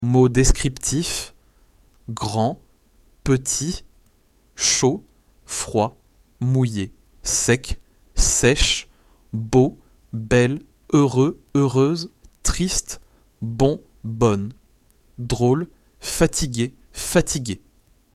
Lesson 7